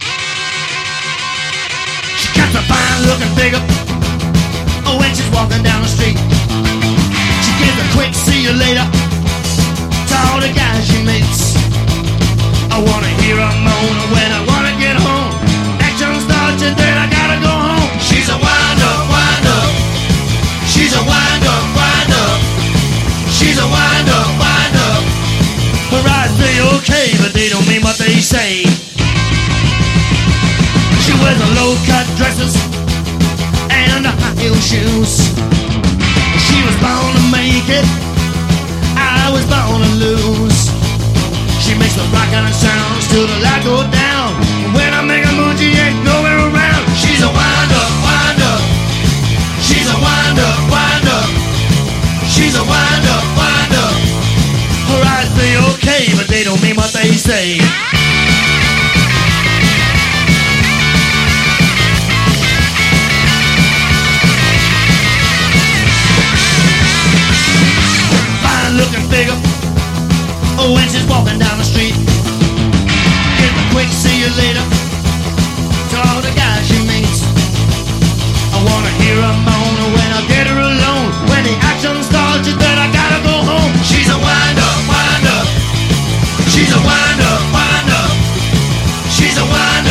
パーカッション・インスト！ボンゴ/ラテンの名盤！
もちろん、ボンゴ/パーカッション・ラウンジ・グルーヴとしても◎！